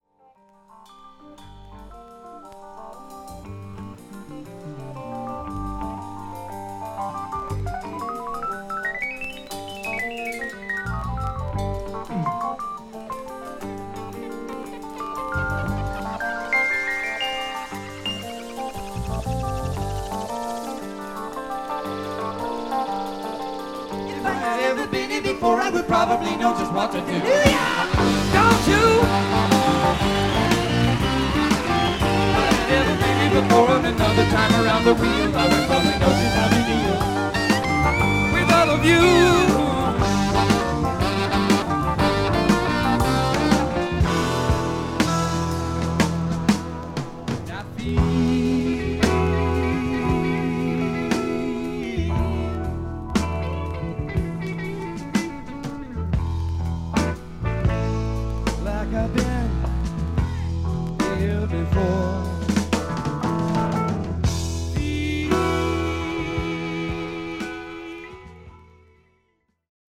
1978年のライブ・アルバム。